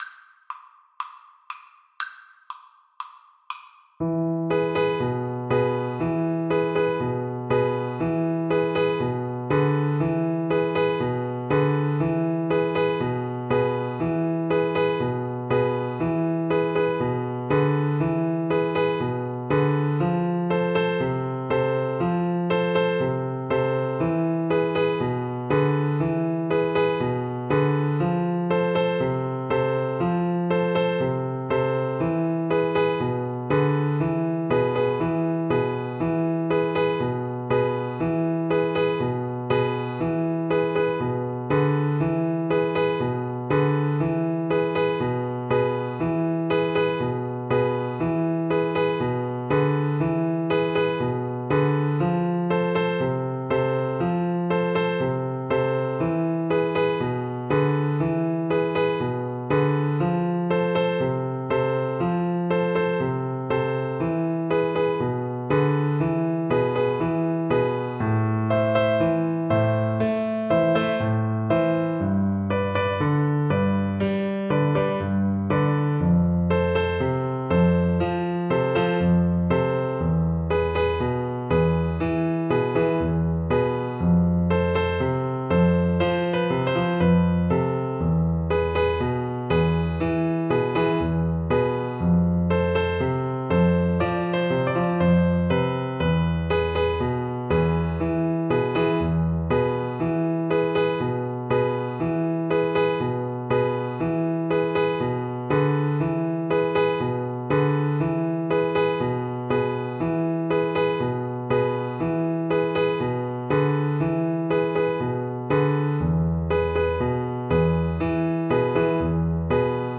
Play (or use space bar on your keyboard) Pause Music Playalong - Piano Accompaniment Playalong Band Accompaniment not yet available transpose reset tempo print settings full screen
French Horn
C major (Sounding Pitch) G major (French Horn in F) (View more C major Music for French Horn )
4/4 (View more 4/4 Music)
Pop (View more Pop French Horn Music)
world (View more world French Horn Music)